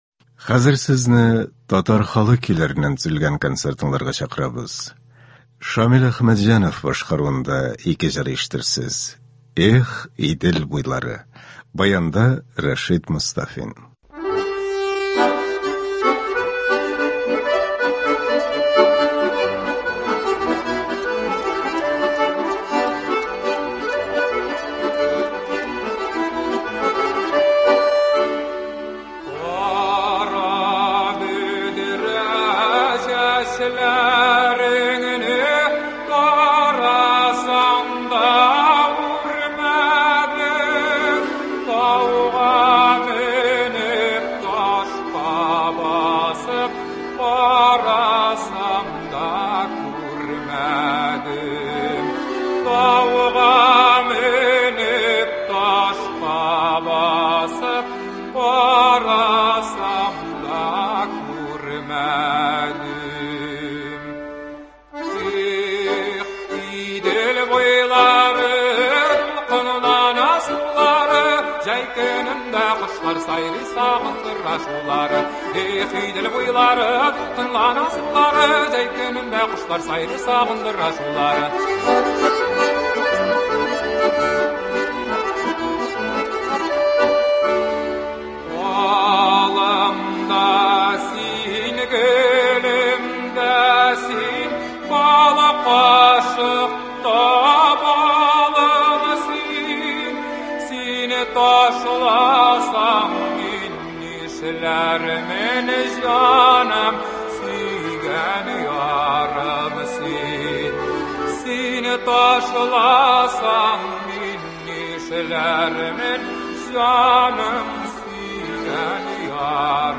Татар халык көйләре (21.01.23)